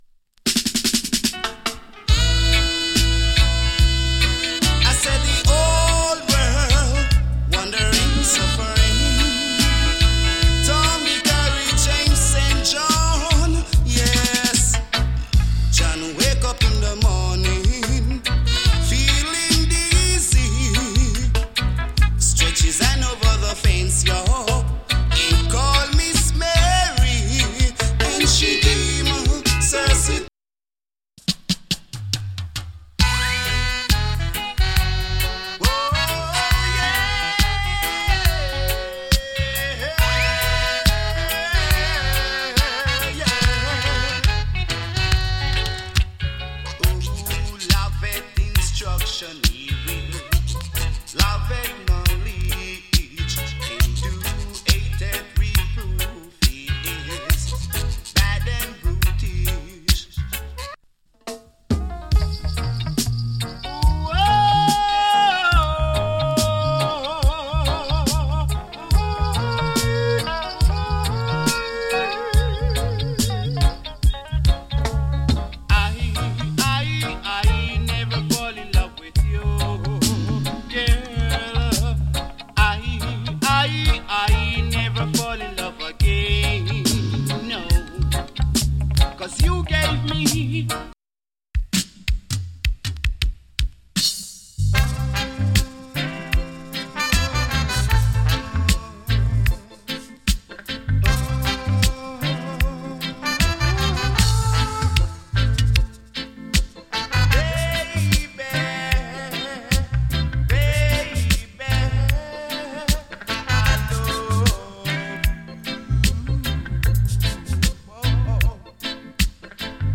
チリ、ジリノイズわずかに有り。